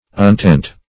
Untent \Un*tent"\